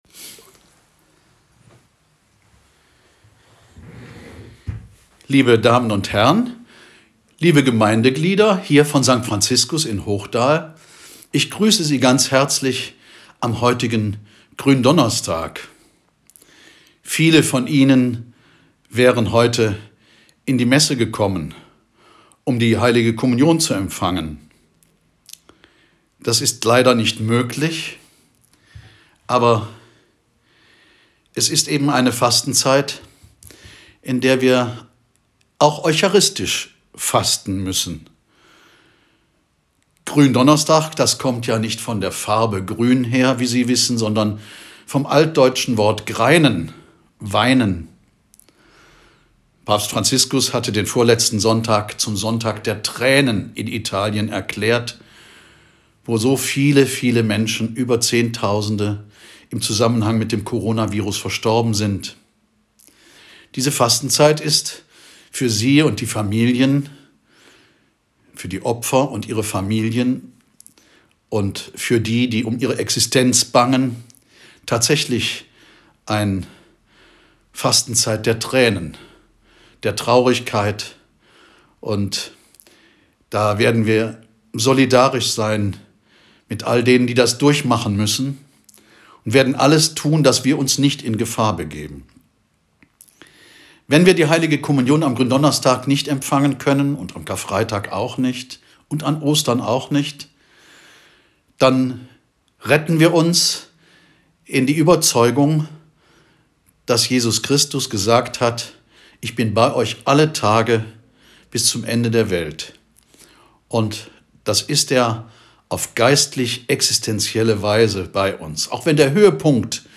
Ansprache